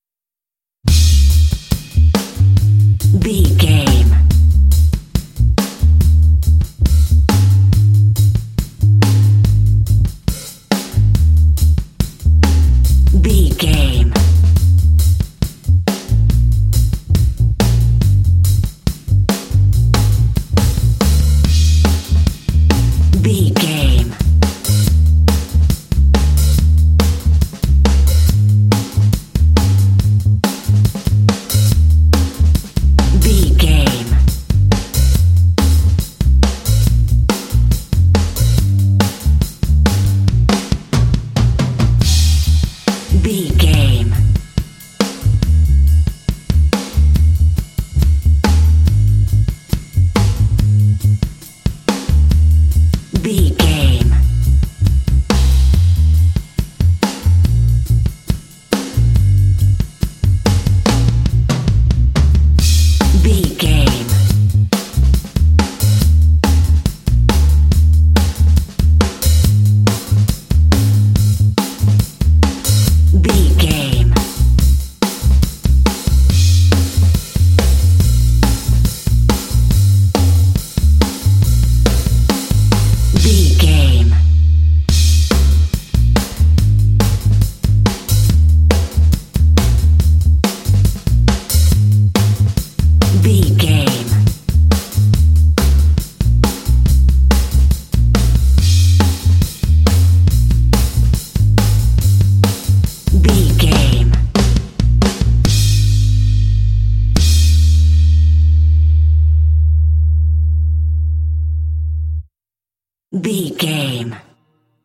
Aeolian/Minor
E♭
funky
groovy
bright
drums
bass guitar
blues
jazz